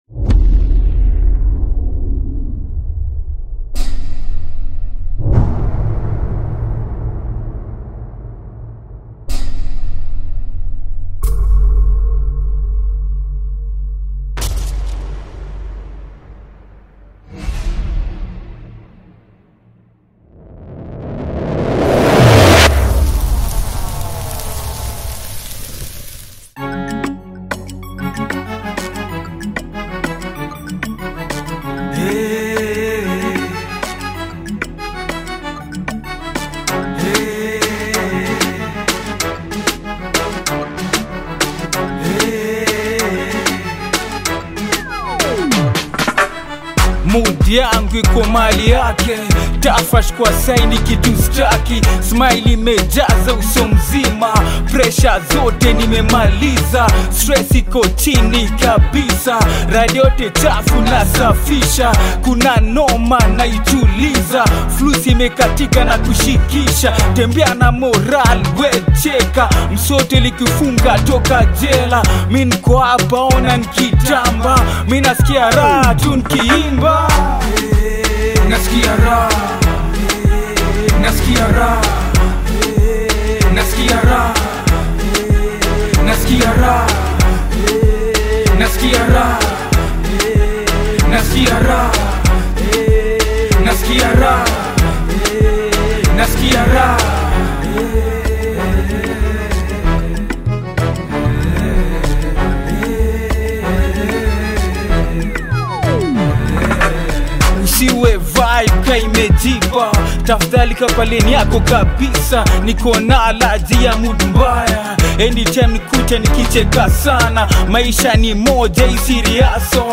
the Kenyan hip-hop artist famous for his stage name